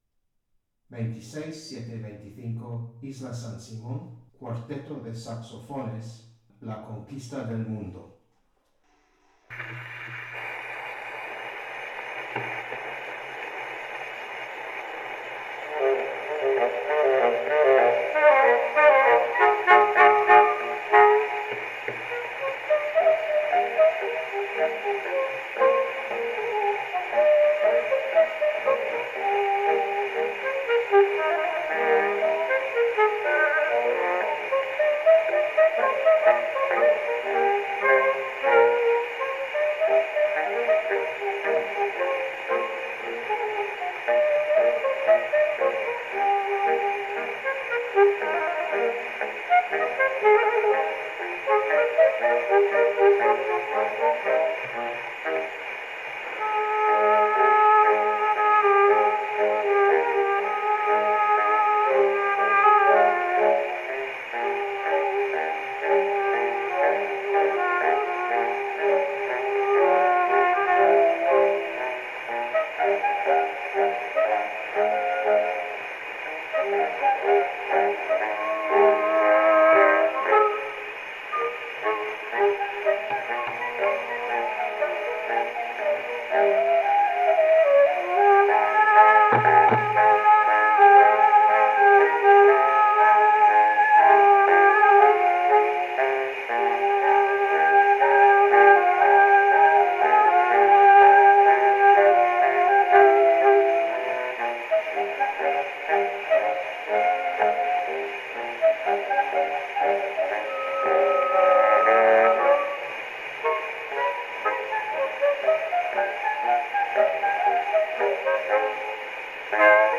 Original sound from the phonographic cylinder.
Track: “La conquista del mundo (Chotis), de Reveriano Soutullo y Juan Vert (1923)”
Recording place: Espazo Cafetería
A baixa fidelidade ten algúns beneficios; os ruídos da superficie inherentes á gravación e reprodución de cilindros funciona de dúas maneiras significativas: crea unha ilusión (ou realidade) dun tempo pretérito —o ruído é como unha pátina, marcas sonoras de sufrimento, desgaste e envellecemento.